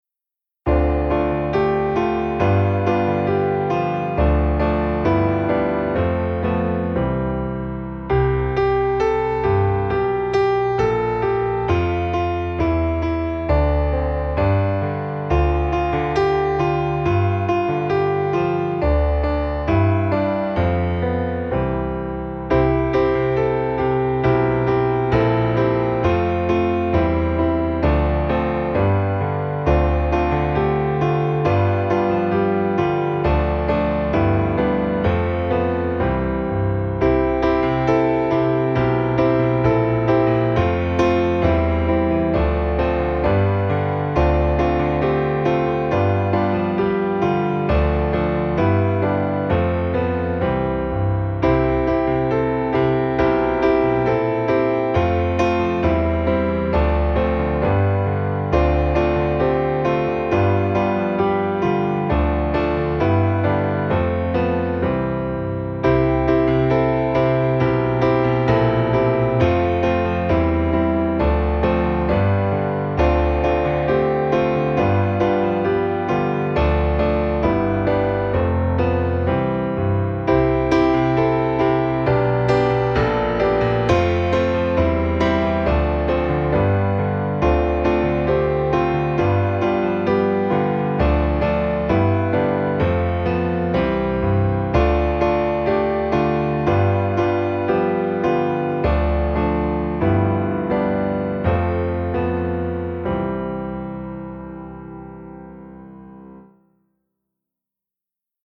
Zvonček (s playbackom)
pieseň zo školenia (2017 Štrba) – noty s akordami, prezentácia a playback